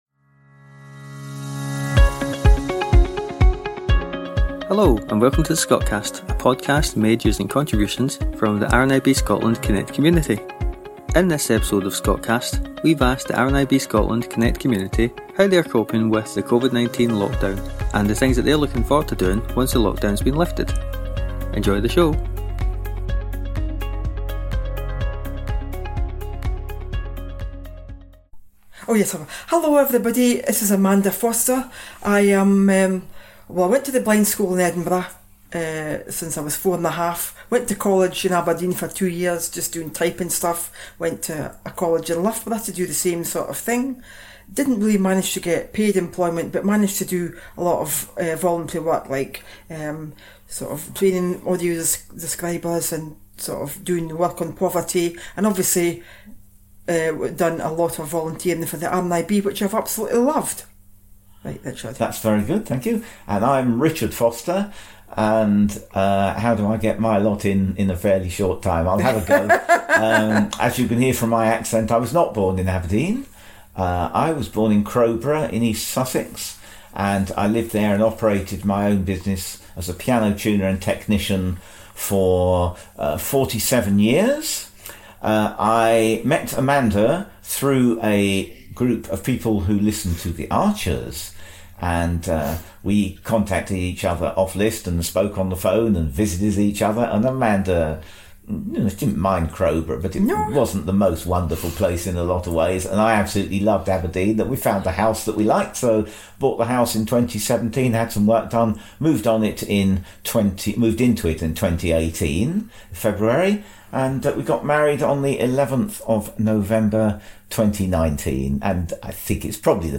Join the RNIB Scotland Connect community on the Scotcast, a monthly podcast made entirely of community member contributions!